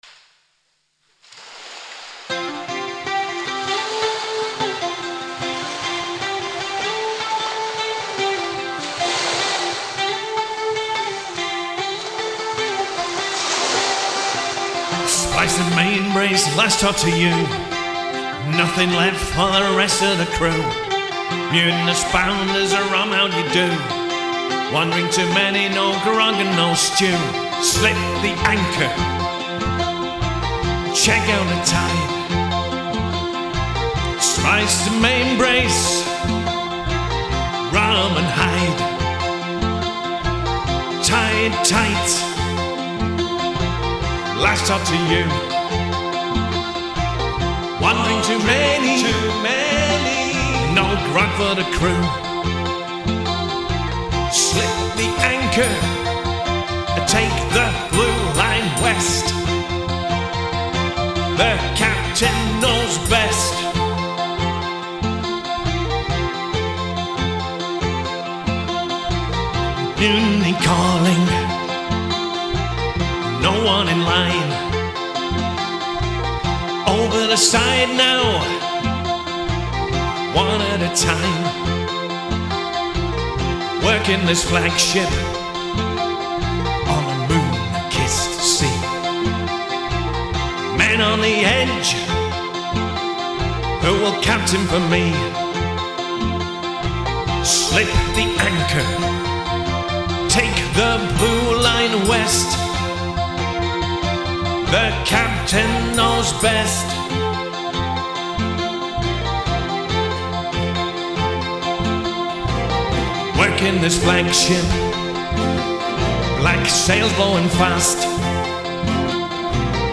vocals/flute/percussion
guitars/mandolin/banjo